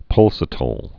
(pŭlsə-təl, -tīl)